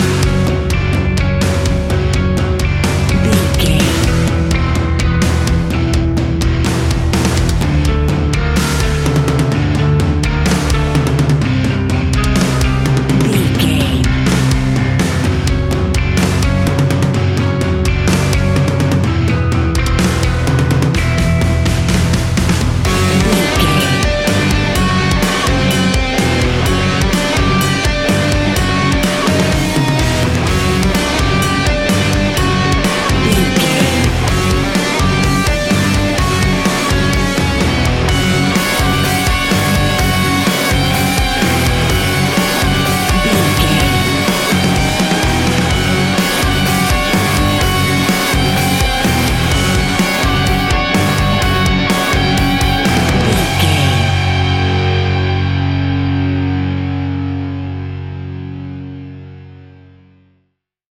Ionian/Major
F♯
hard rock
guitars
heavy metal
instrumentals